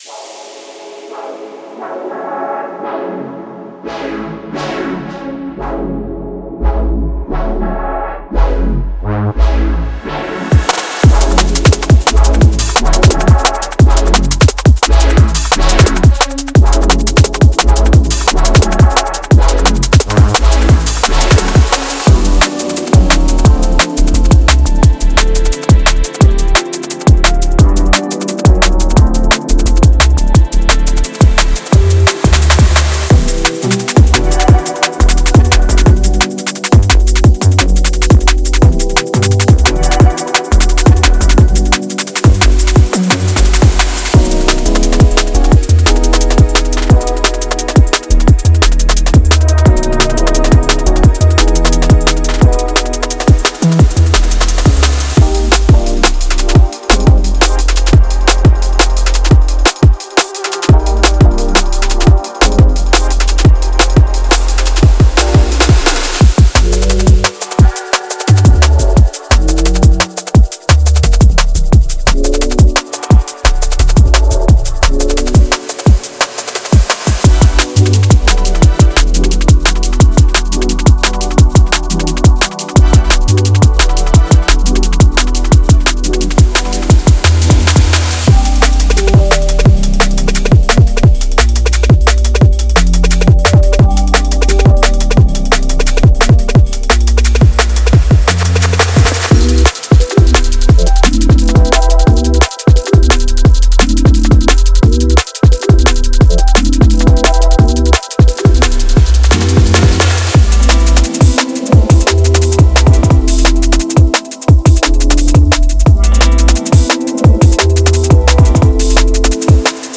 Genre:Liquid
そこは、深いベースライン、転がるドラム、そしてきらめくメロディが織り成す空間です。
FXセクションは、スウィープ、ダウンシフター、空気感のあるボーカルチョップを使って、ムードに動きと奥行きを加えます。
デモサウンドはコチラ↓